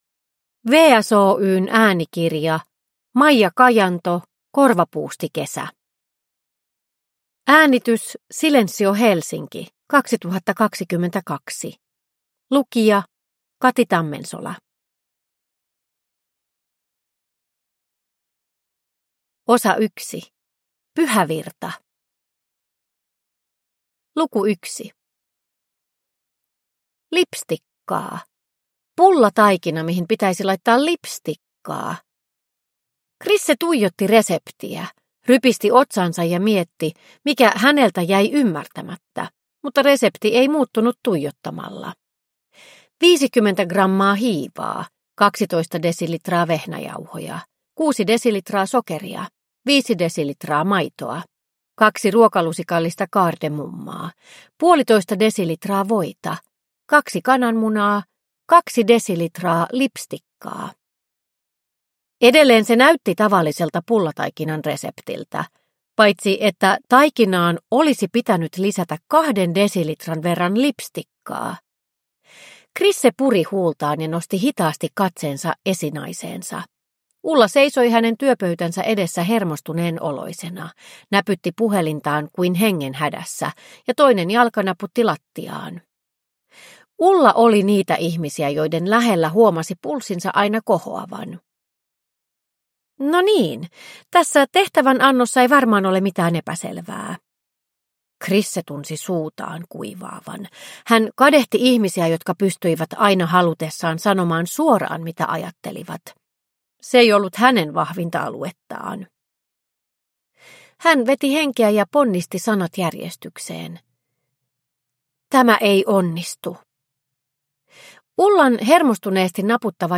Korvapuustikesä – Ljudbok – Laddas ner